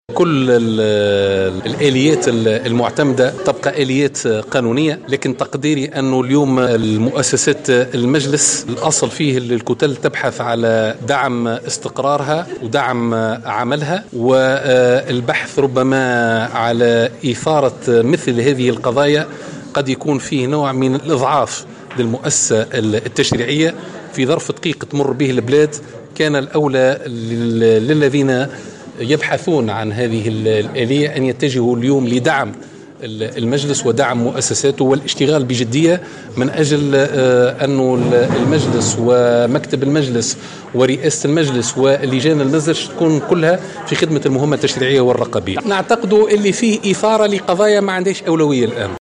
أكّد النّاطق الرسمي بإسم حركة النهضة عماد الخميري في تصريح لمراسلة الجوهرة "اف ام" تعليقا على عريضة سحب الثقة من رئيس البرلمان راشد الغنوشي أنها اثارة لقضايا ليس لها أولية في الظرف الراهن.